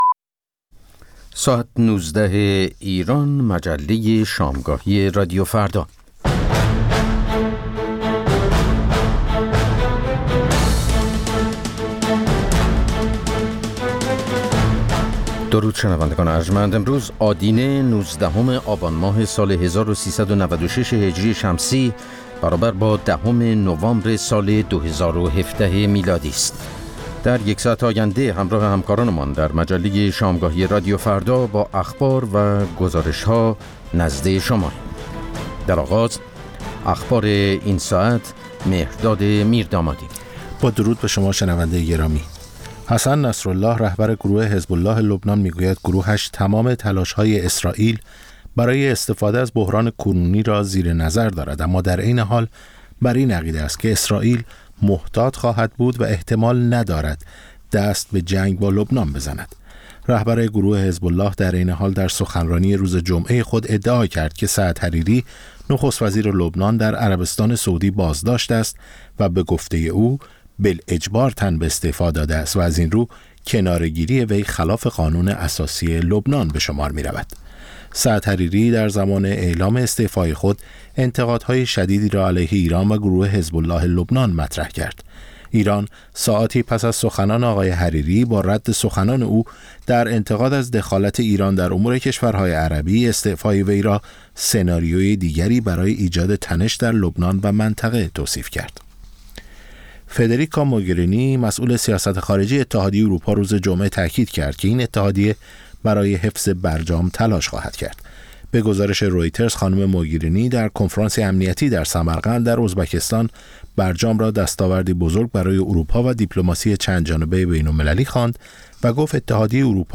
مجموعه‌ای متنوع از آنچه در طول روز در سراسر جهان اتفاق افتاده است. در نیم ساعت اول مجله شامگاهی رادیو فردا، آخرین خبرها و تازه‌ترین گزارش‌های تهیه‌کنندگان رادیو فردا پخش خواهد شد. در نیم ساعت دوم شنونده یکی از مجله‌های هفتگی رادیو فردا خواهید بود.